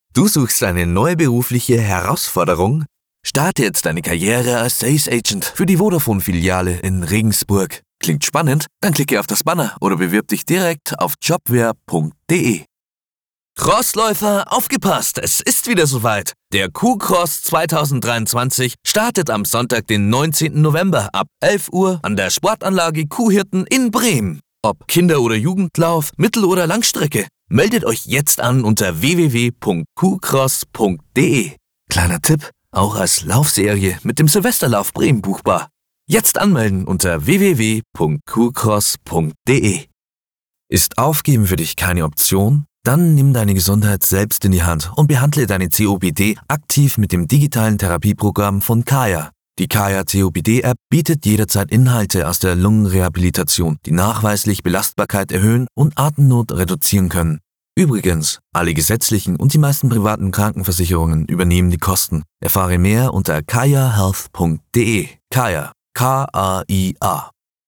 Radio Ad Spotify
High german, bavarian